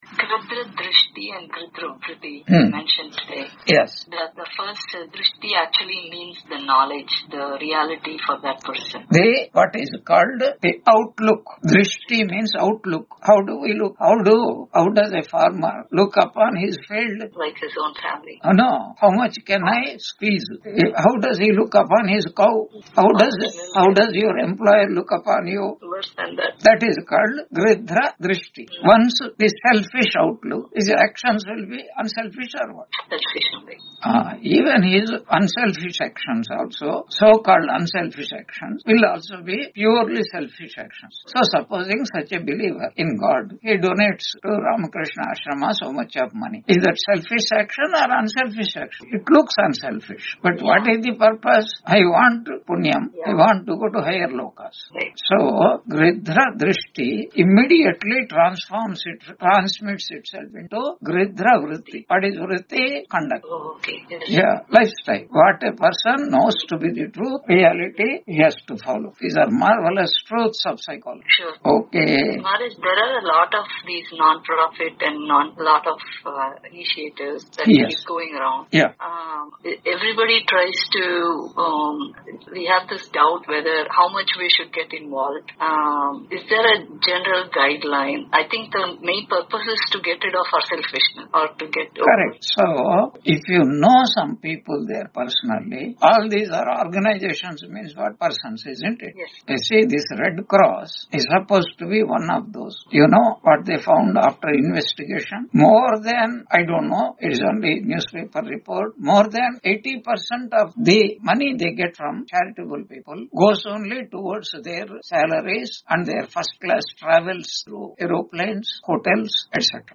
Q&A Session